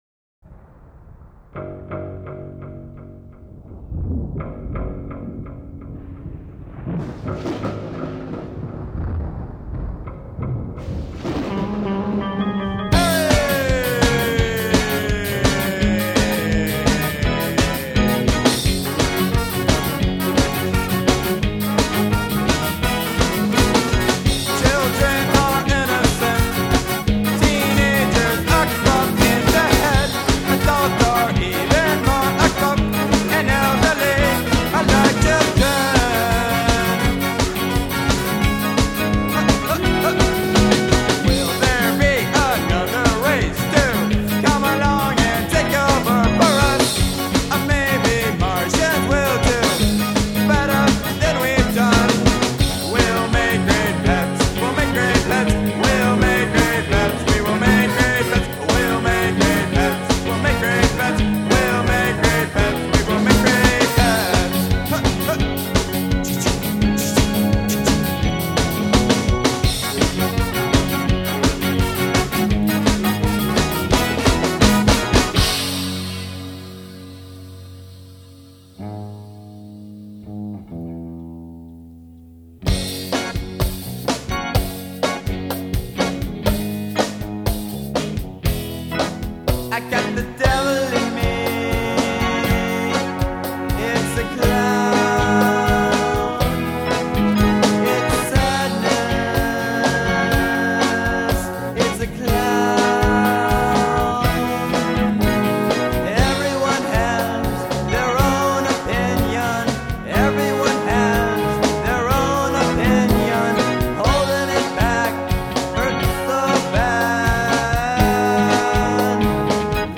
In SKA FORM